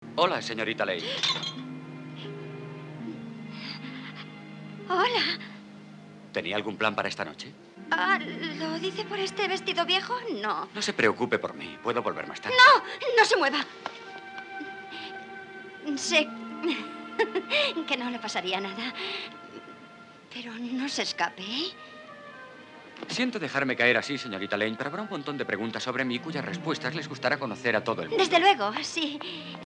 Esta carencia ha sido subsanada por Warner en esta nueva edición, puesto que el primer disco -montaje de cine- recupera el doblaje original español, en formato monoaural. Su calidad es relativamente mediocre, con una dinámica muy limitada y rozando frecuentemente la distorsión -además de no ofrecer ninguna direccionalidad-, pero por lo menos ahora sí está presente.
doblaje original (disco 1) y con su
Excelente audio 5.1, incluye doblaje original mono